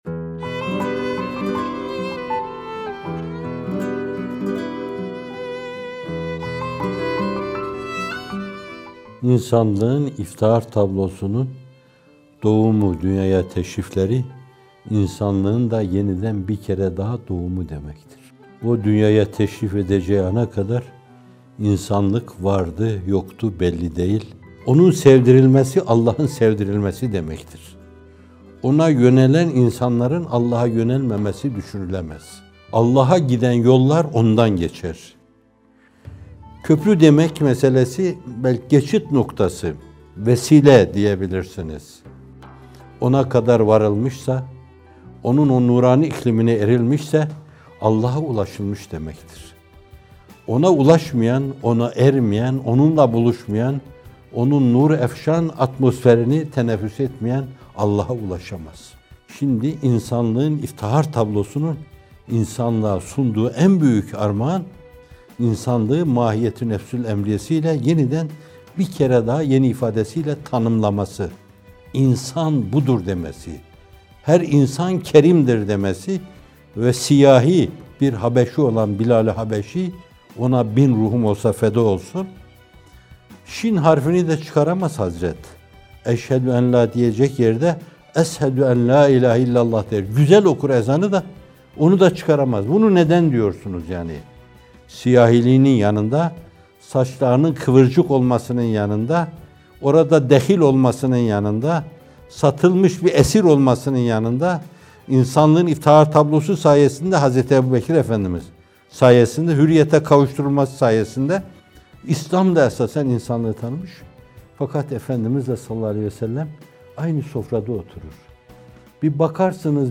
Muhterem Hocaefendi, merhum Mehmet Akif’in “Bir Gece” şiirini okuyor: